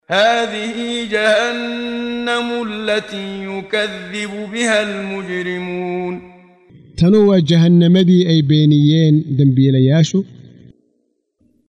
Waa Akhrin Codeed Af Soomaali ah ee Macaanida Suuradda Ar-Raxmaan ( Naxariistaha ) oo u kala Qaybsan Aayado ahaan ayna la Socoto Akhrinta Qaariga Sheekh Muxammad Siddiiq Al-Manshaawi.